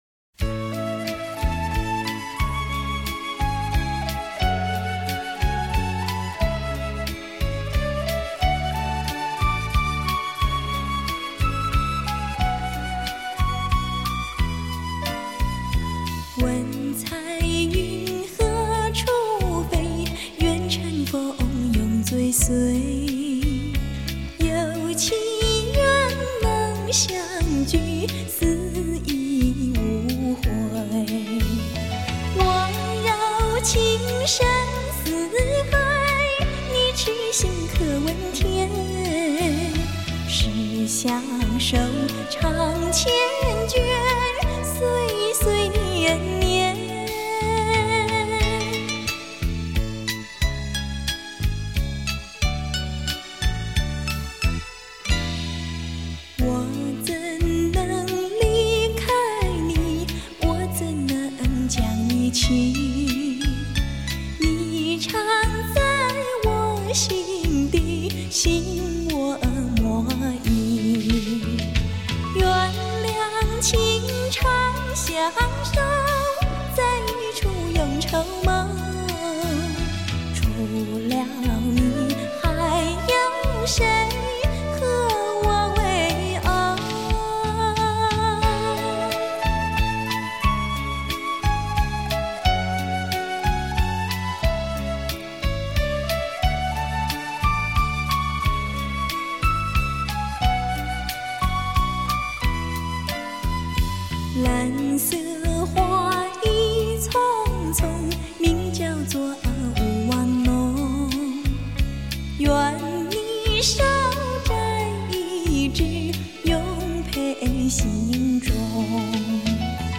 伴奏音乐力求简练优美，全面保留原曲及当代流行的韵味，倍增绵绵之旧日情怀。